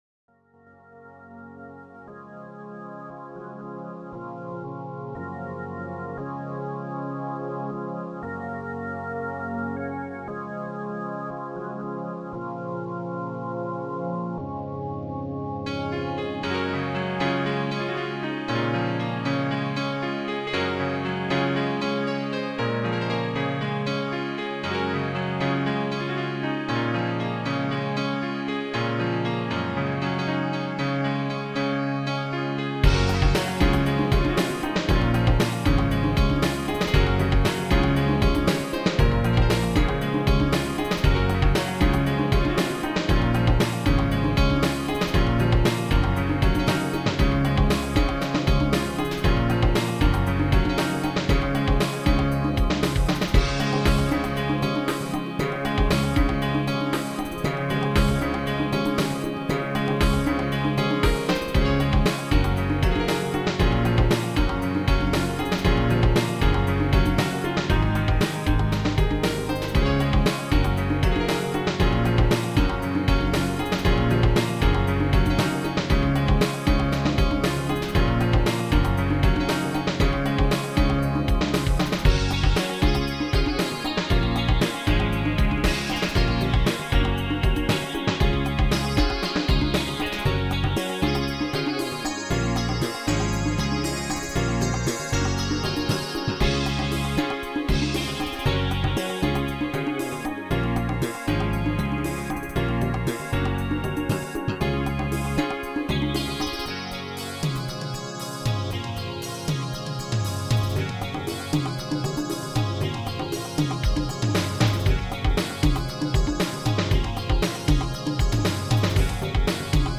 [ Original Format : Impulse Tracker